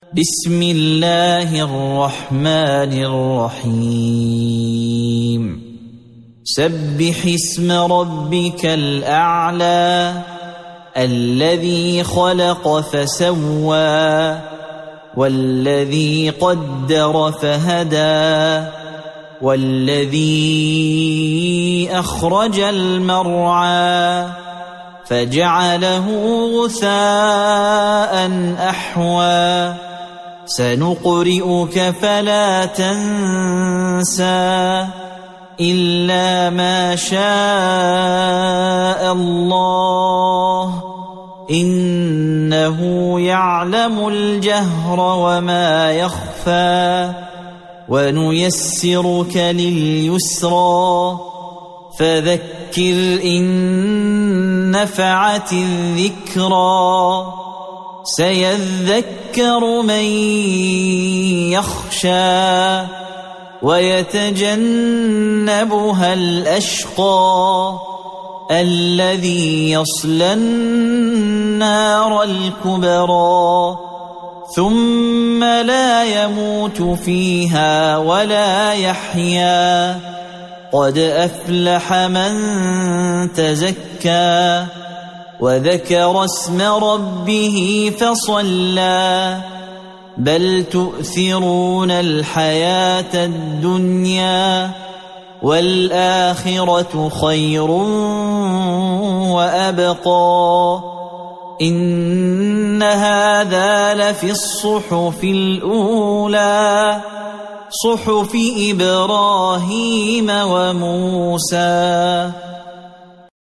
سورة الأعلى | القارئ